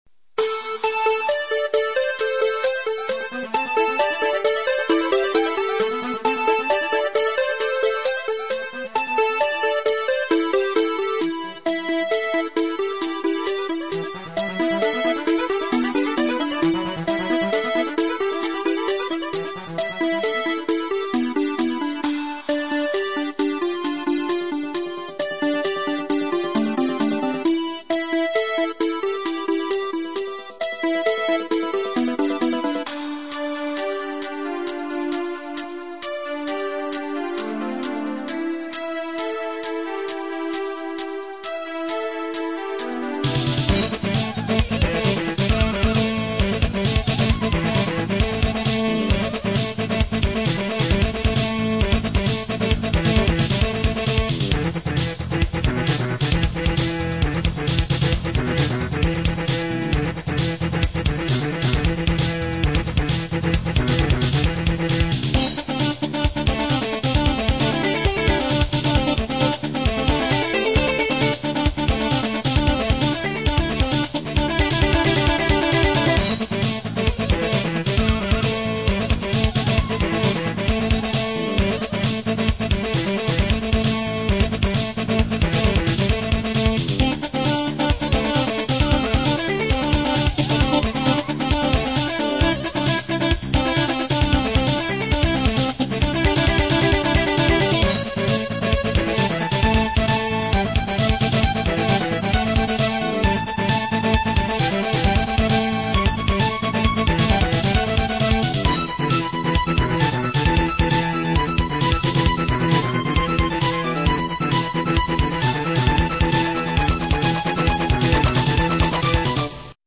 recorded midi on